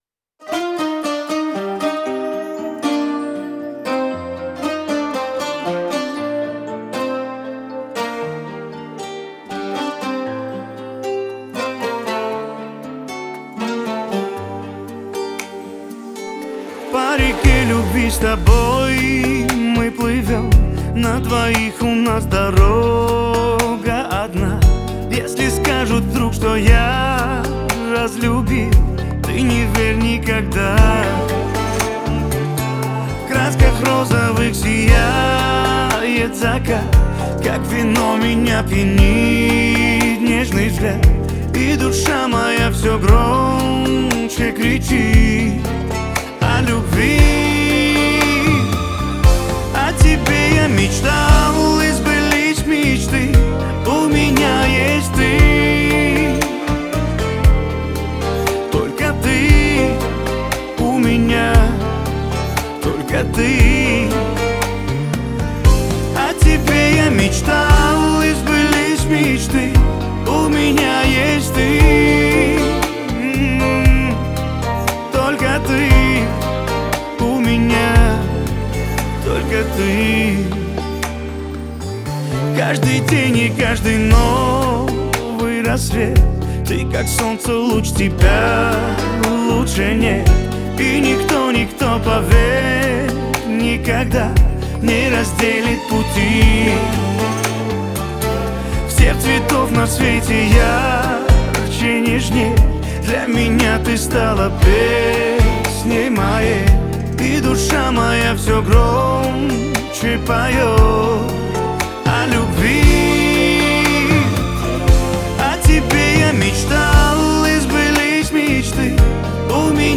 Русский шансон